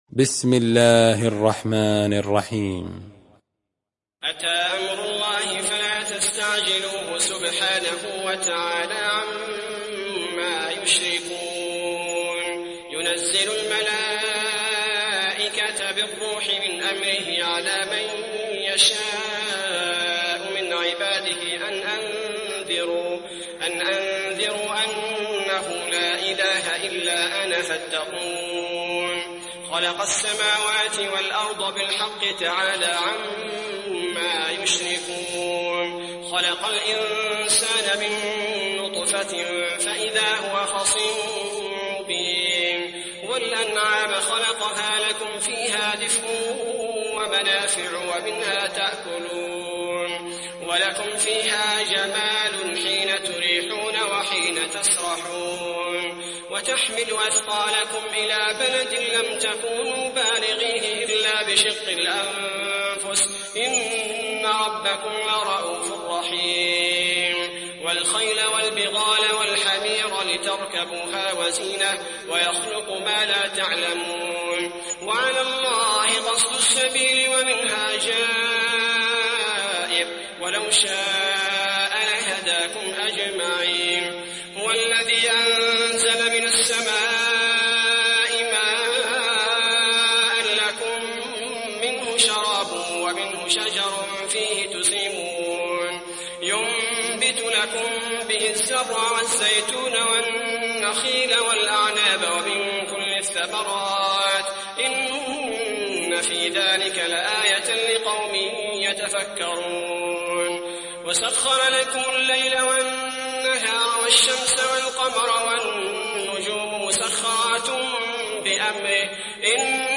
تحميل سورة النحل mp3 بصوت عبد الباري الثبيتي برواية حفص عن عاصم, تحميل استماع القرآن الكريم على الجوال mp3 كاملا بروابط مباشرة وسريعة